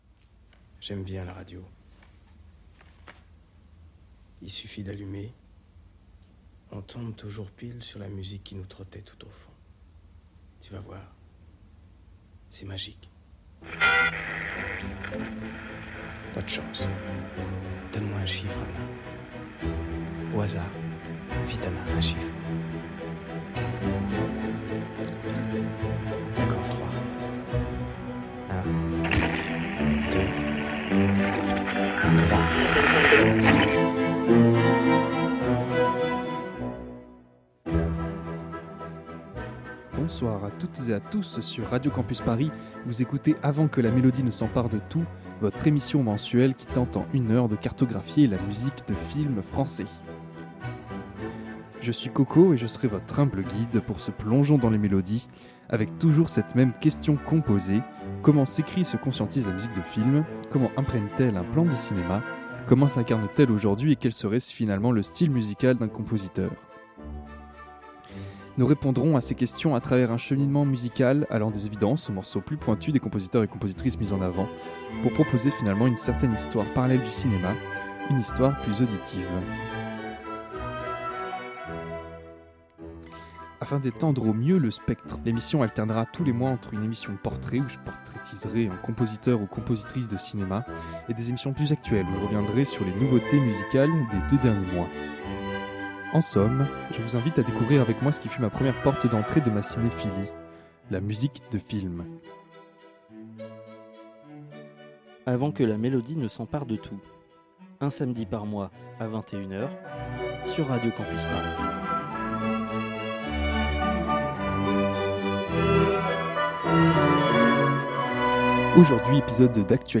Musicale Classique & jazz